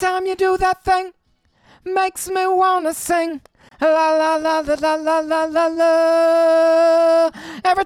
everytimevoxMain.wav